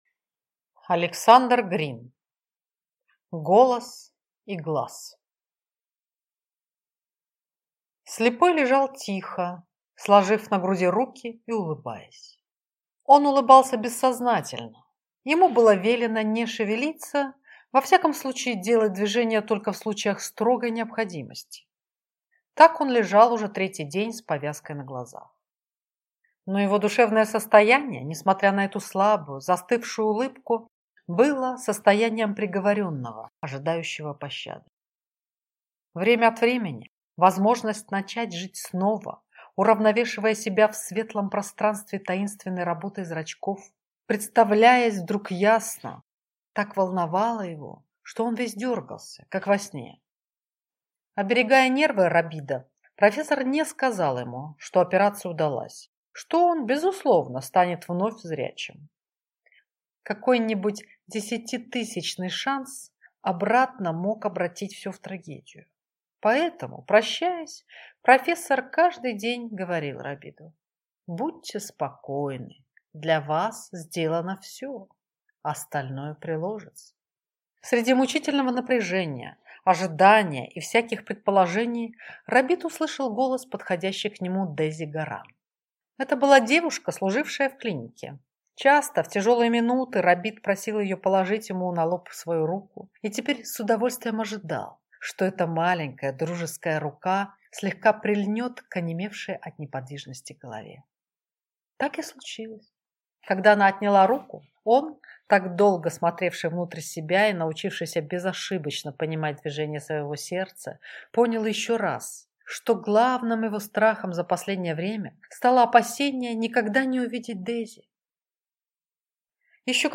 Аудиокнига Голос и глаз | Библиотека аудиокниг